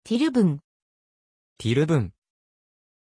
Aussprache von Tjorven
pronunciation-tjorven-ja.mp3